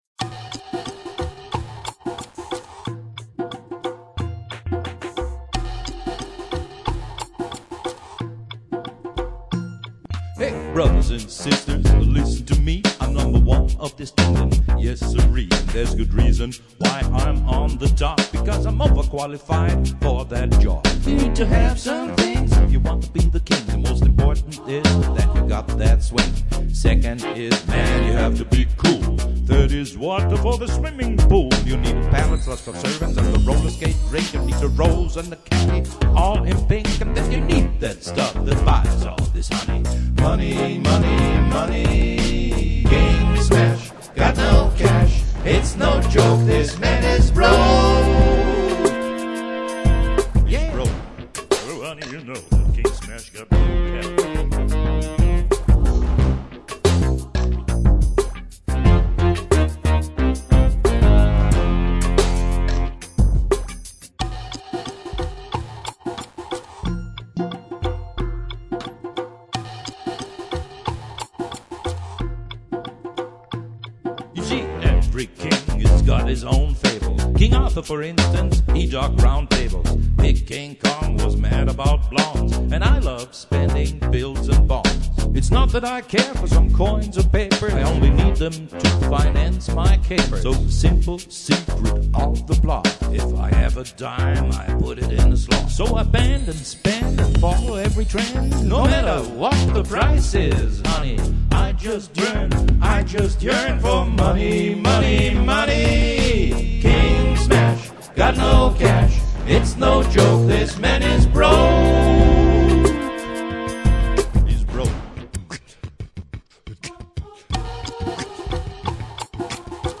- FANFARE - Auftritt King Smash, auf Rollschuhen: